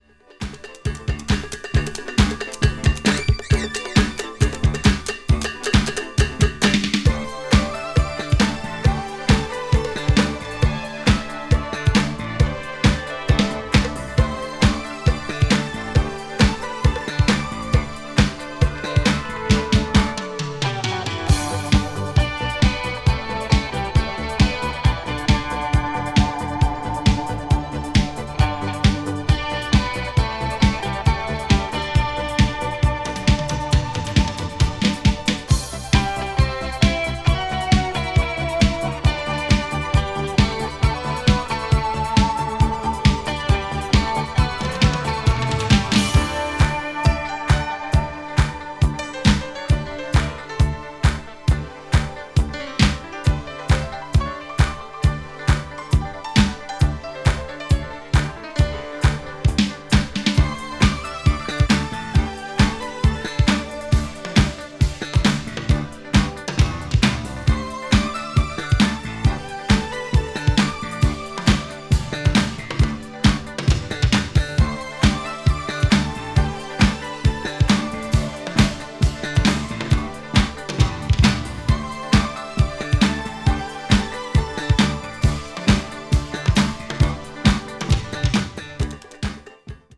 crossover   fusion   jazz groove   obscure dance   synth pop